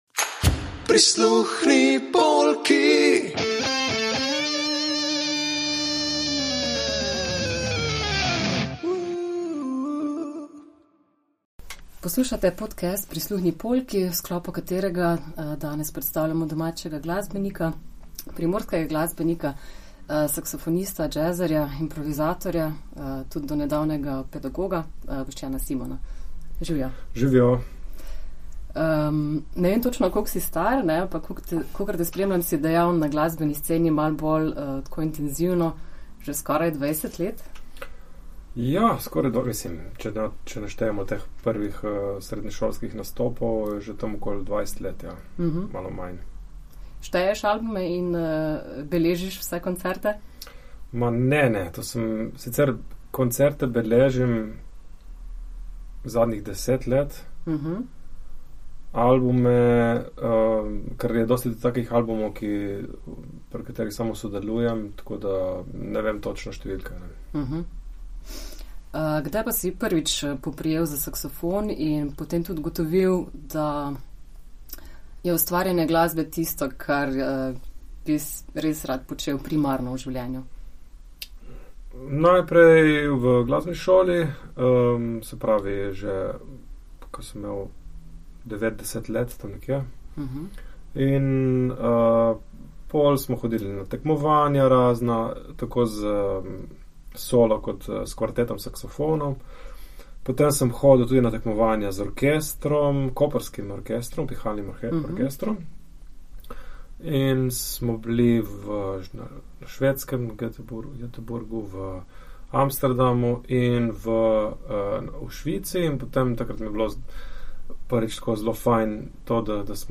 prisluhnite v pogovoru